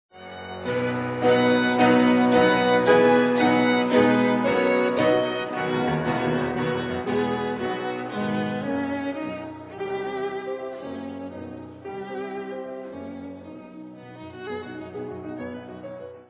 Beispiel: Nepaolitaner in Dur-Kadenz
Robert Schumann, Sonate d-Moll, für Violine und Klavier, op. 121, 2. Satz, T. 204 - 216
Am Ende des zweiten Satzes von Robert Schumanns großer Violinsonate in d-Moll erscheint zweimal nacheinander der seltene Fall eines neapolitanischen Sextakkordes in einer Dur-Kadenz. Die in dieser Umgebung sehr fremdartige Harmonie steht in besonderem Kontrast zu dem zuvor fortissimo gespielten Choral in H-Dur.